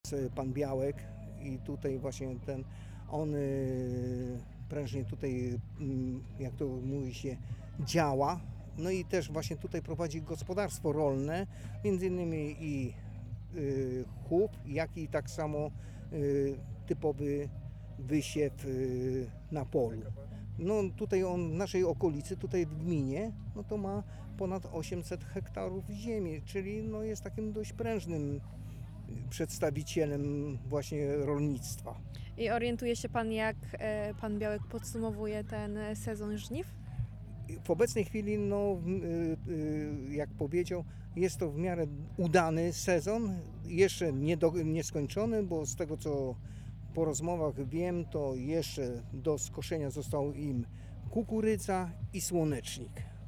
Co dla nich oznaczało święto plonów i jakie refleksje płyną z mijającego roku? O tym opowiedzieli sołtysi, którzy najlepiej znają znaczenie tych wydarzeń dla swojej społeczności.